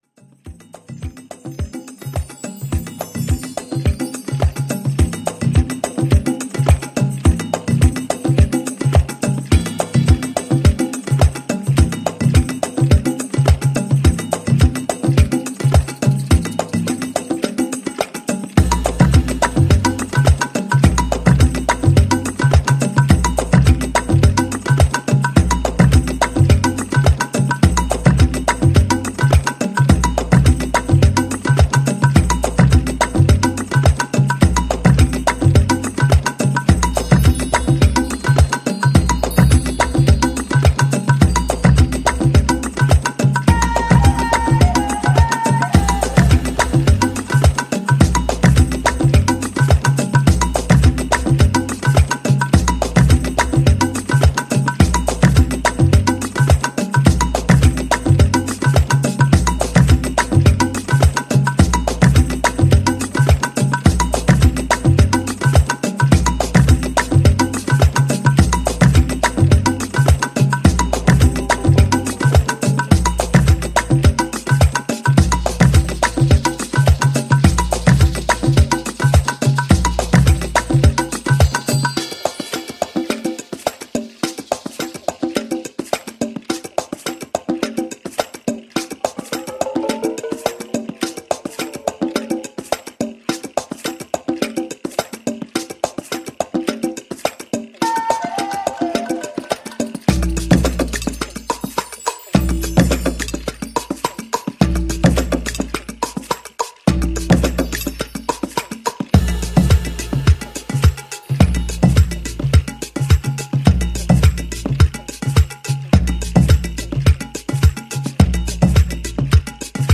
intricate landscapes of percussive beauty
restrained, hypnotic approach
organic house workouts with rich orchestrations